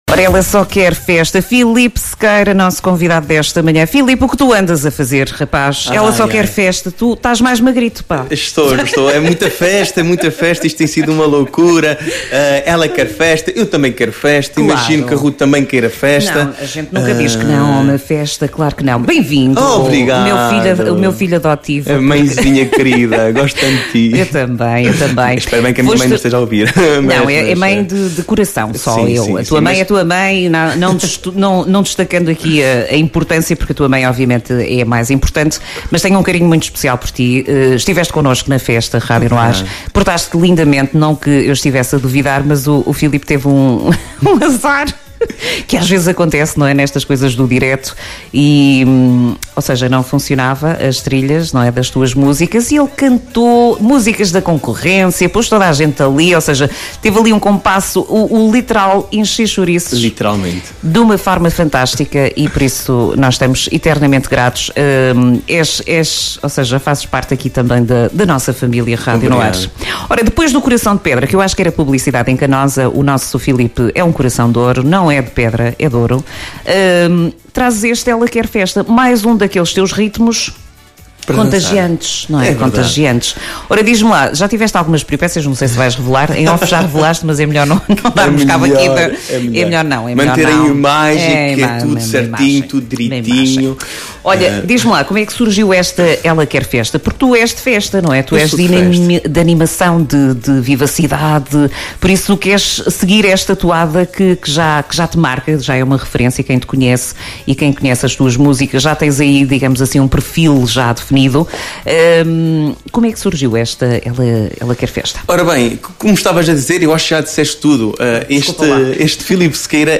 Notícias Recentes